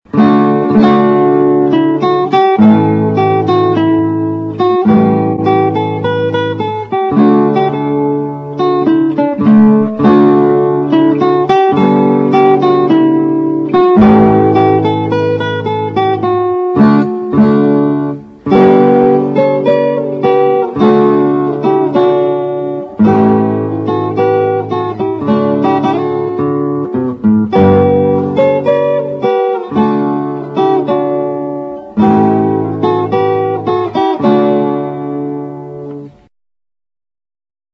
relaxed slow instr.